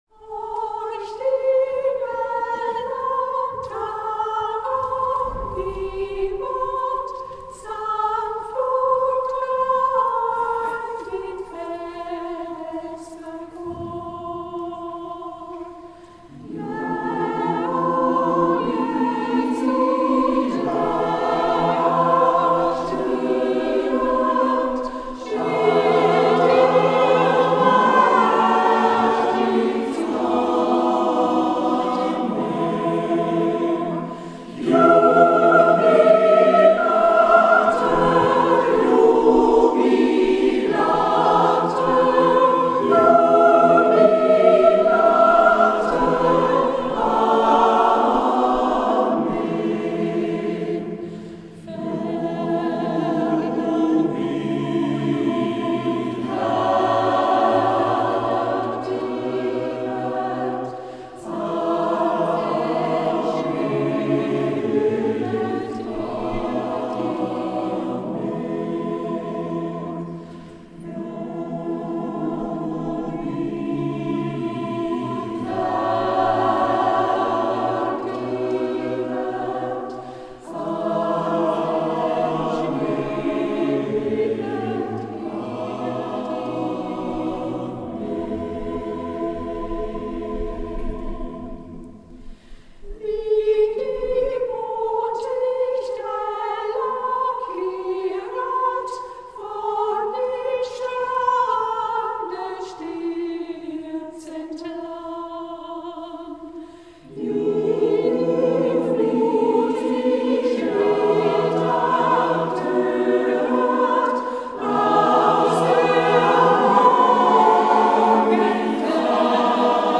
Herkunft / Text: Russischer Vespergesang
Anlass: Adventskonzert
Aufnahmeort: Stiftskirche Obernkirchen